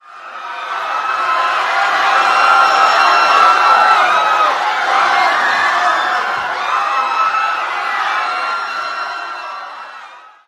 crowd panic sound effect mp3cut from all sound effects
crowd-panic-sound-effect-mp3cut